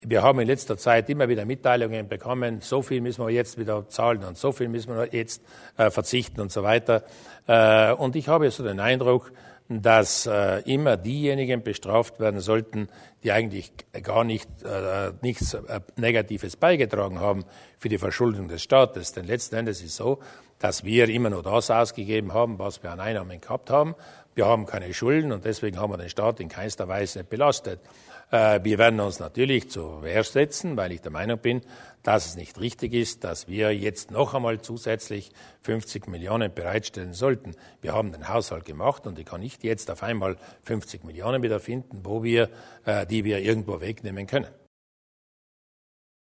Landeshauptmann Durnwalder über Sparmaßamen der Regierung und dessen Anfechtung